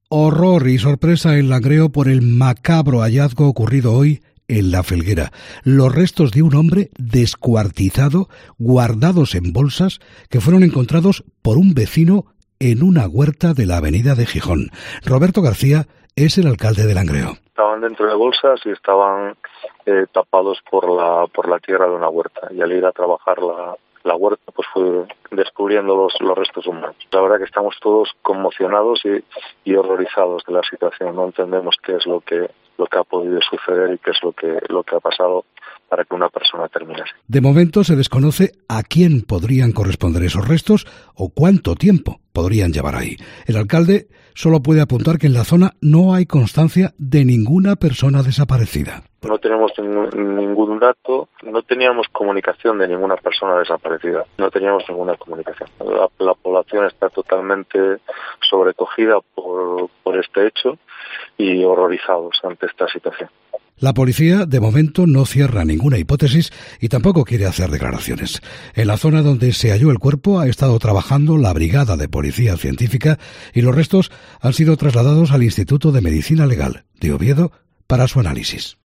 Hablamos con el alcalde de Langreo sobre el macabro hallazgo en La Felguera